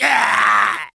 troll_archer_die.wav